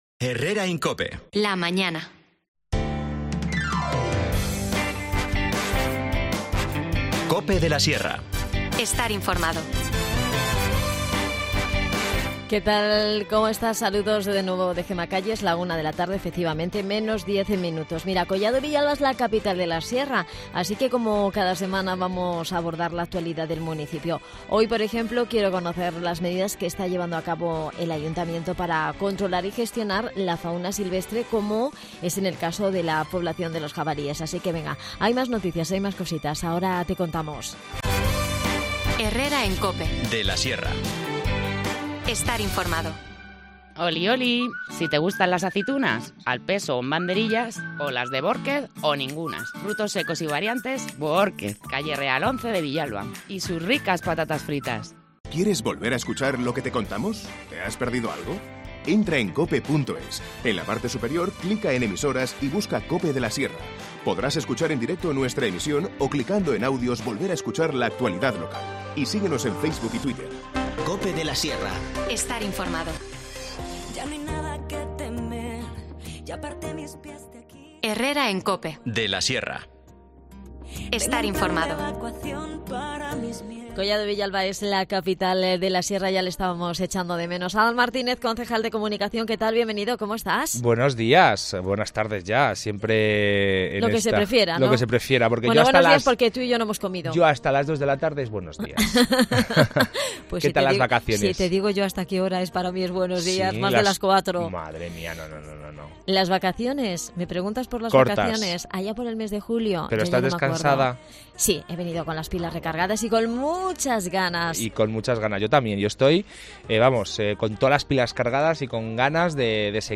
Redacción digital Madrid - Publicado el 14 sep 2023, 13:19 - Actualizado 15 sep 2023, 09:26 3 min lectura Descargar Facebook Twitter Whatsapp Telegram Enviar por email Copiar enlace Adan Martínez, concejal de Comunicación en Collado Villalba, Capital de la Sierra, nos visita para hablarnos del Programa de control de la población de jabalíes en localidad. En los últimos tres años se han capturado más de 70 ejemplares.